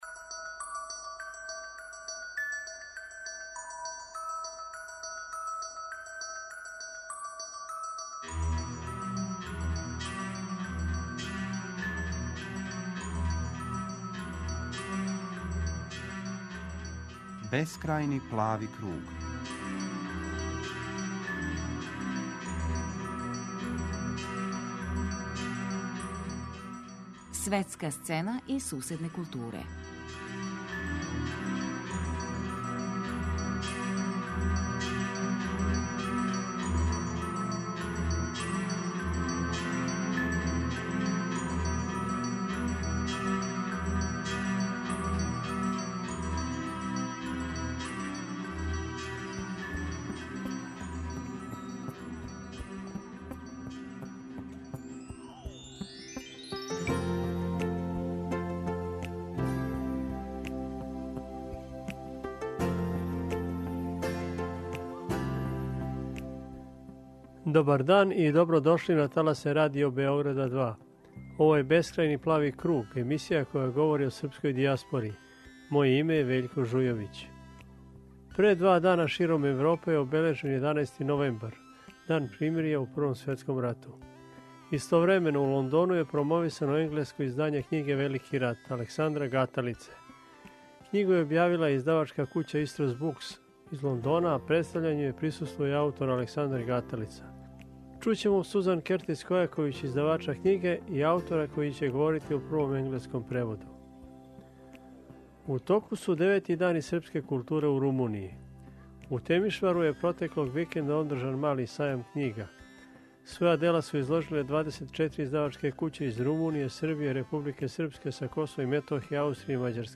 О овој манифестацији имамо репортажу са лица места, из Темишвара.